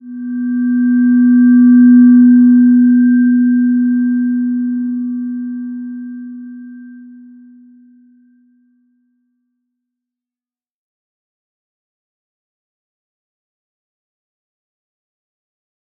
Slow-Distant-Chime-B3-mf.wav